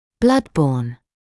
[blʌd bɔːn][блад боːн]переносимый кровью